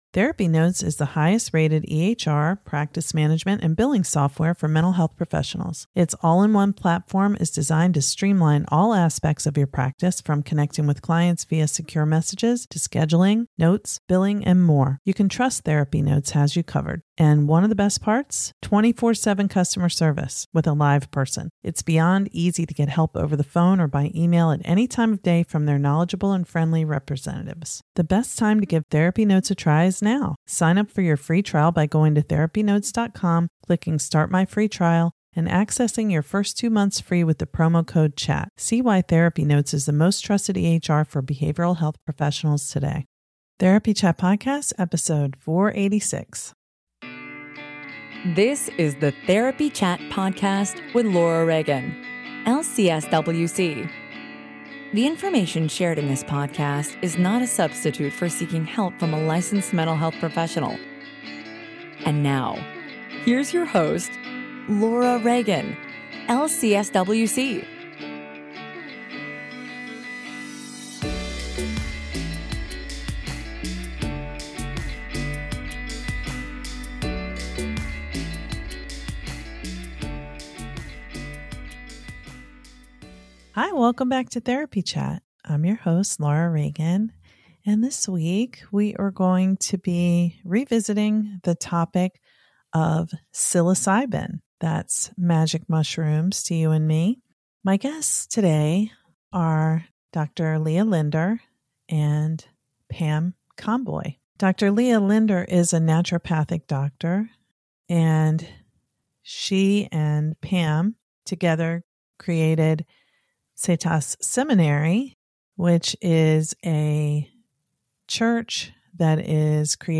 a host on the New Books Network